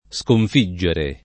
Skonf&JJere] v. («vincere»; «sconficcare»); sconfiggo [Skonf&ggo], -gi — pass. rem. sconfissi [